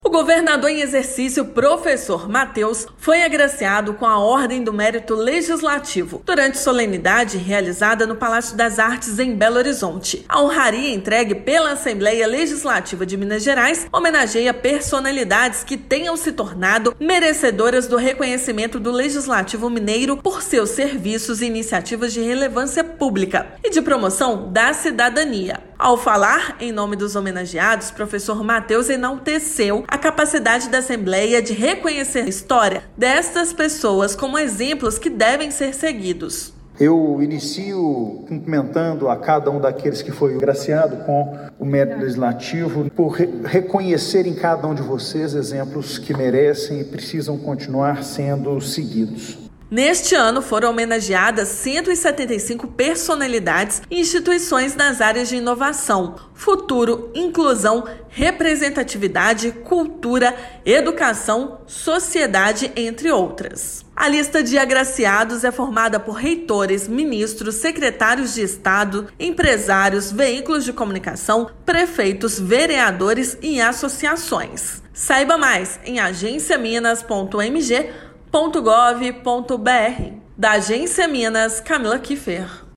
Honraria é concedida pela Assembleia Legislativa de Minas a personalidades e instituições que se destacam por seu serviço e iniciativa em prol da sociedade mineira. Ouça matéria de rádio.